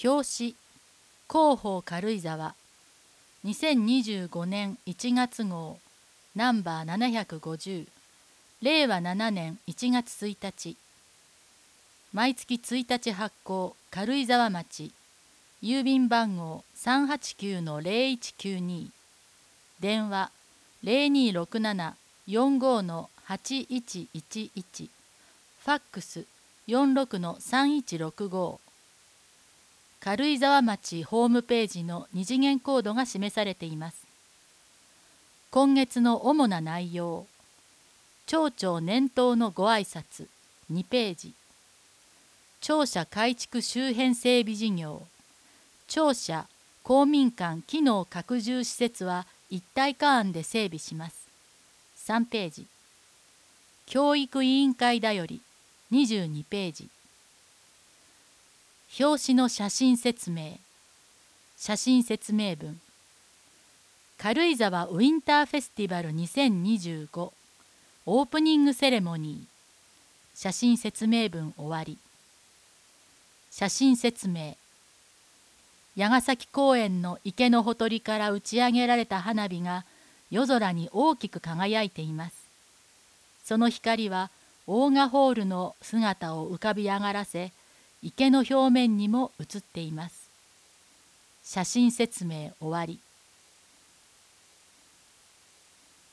音声データ　軽井沢図書館朗読ボランティア「オオルリ」による朗読です